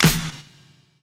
TESA_CLAP_2.wav